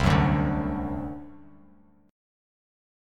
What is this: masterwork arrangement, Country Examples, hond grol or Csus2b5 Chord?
Csus2b5 Chord